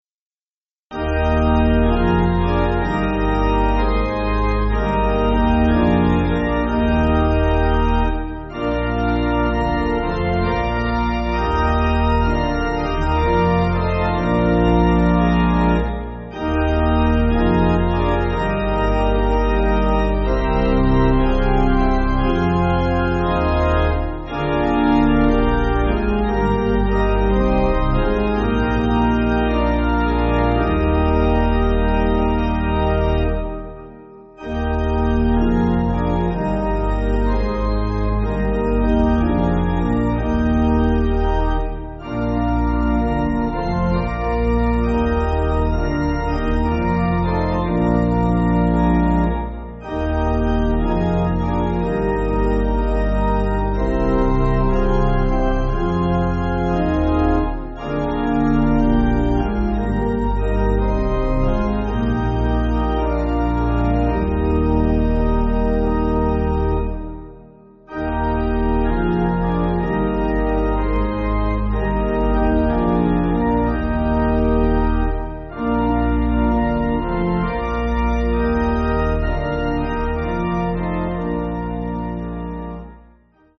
Organ
(CM)   8/Eb